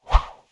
Close Combat Swing Sound 34.wav